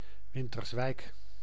Winterswijk (Sebutan Belanda: [ˌʋɪntərsˈʋɛik] (
Nl-Winterswijk.ogg